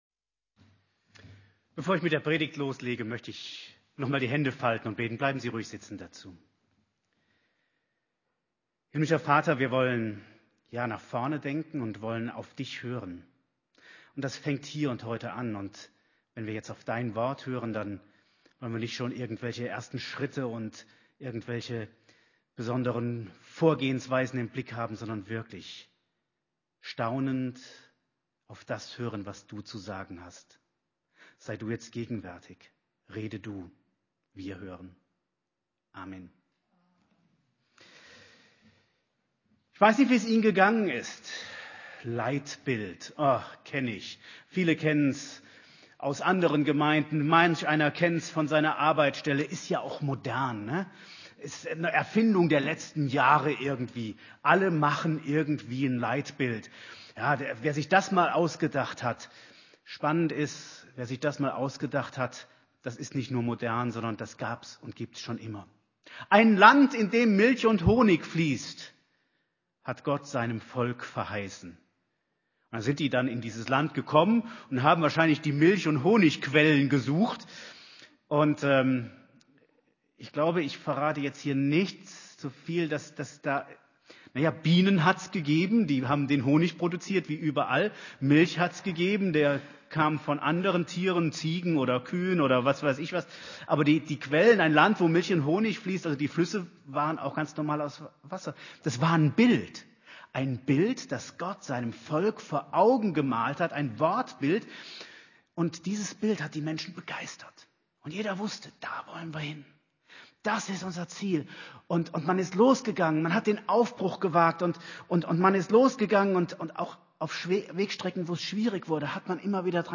Gestartet haben wir im Gottesdienst am 3. November, in dem wir erste Impulse von allen Besuchern erfragten.